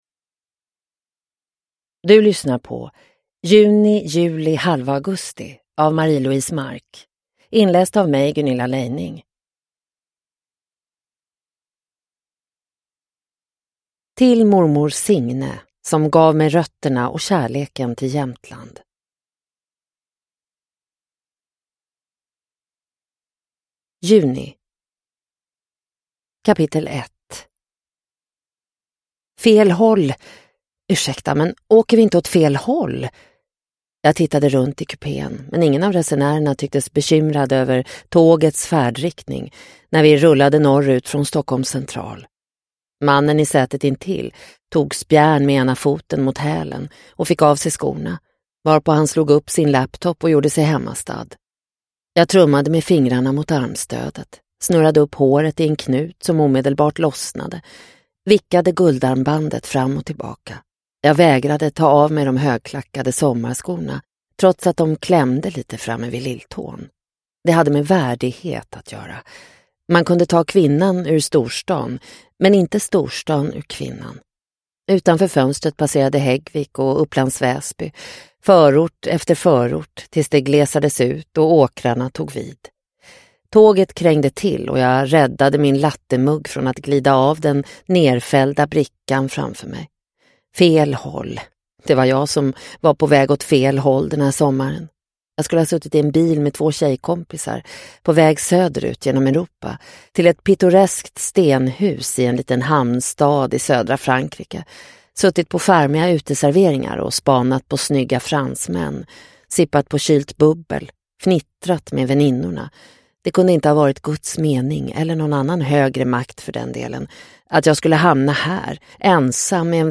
Juni, juli, halva augusti – Ljudbok – Laddas ner